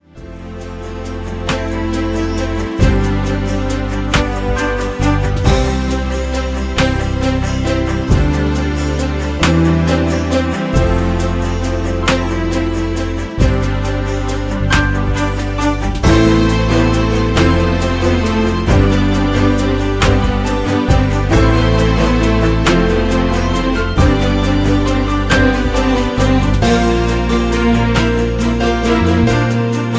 Backing track Karaoke
Pop, Musical/Film/TV, Duets, 2000s